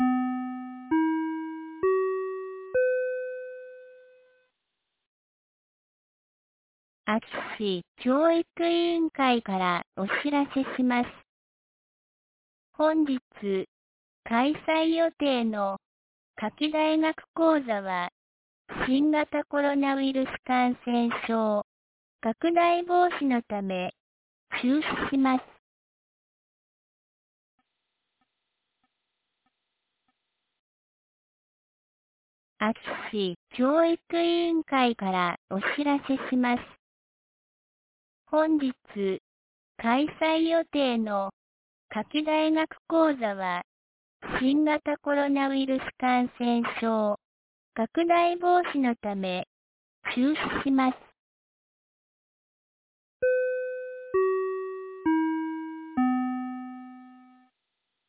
2022年09月10日 17時11分に、安芸市より全地区へ放送がありました。